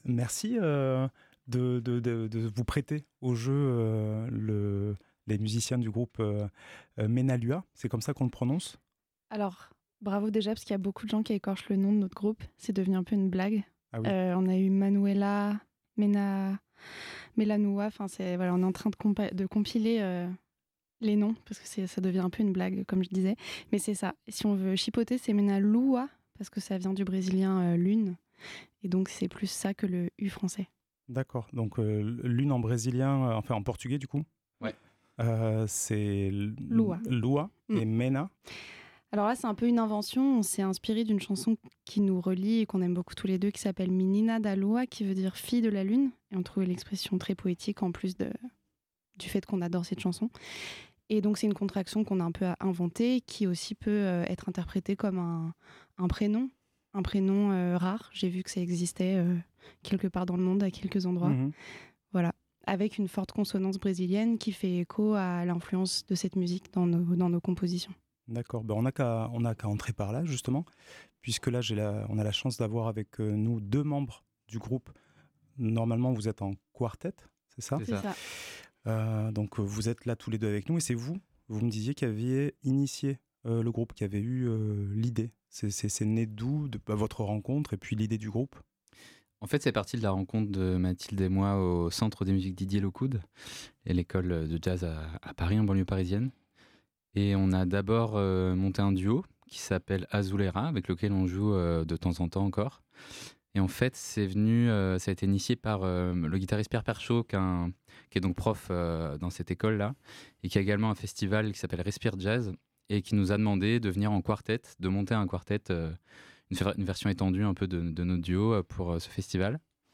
L'entretien du groupe durant le festival CrestJazz 2025 en intégralité